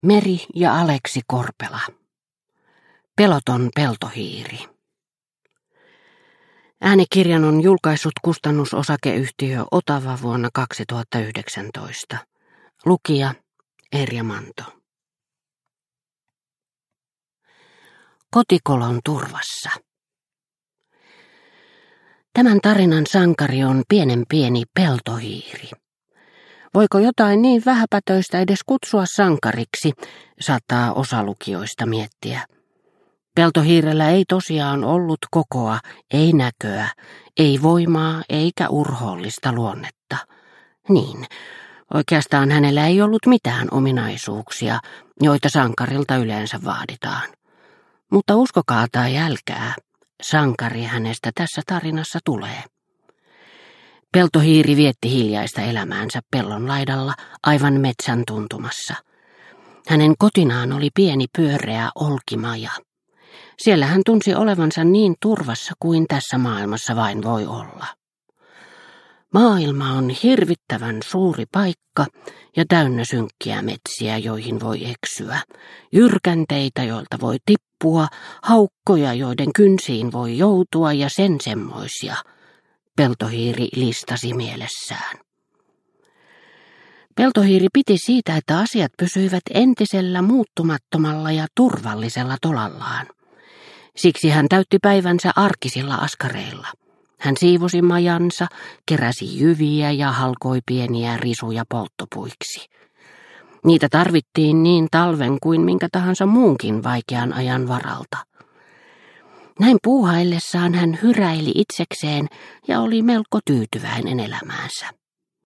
Peloton peltohiiri – Ljudbok – Laddas ner